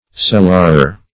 Search Result for " cellarer" : The Collaborative International Dictionary of English v.0.48: Cellarer \Cel"lar*er\, n. [LL. cellararius, equiv. to L. cellarius steward: cf. F. cell['e]rier.